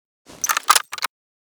mosin_load.ogg.bak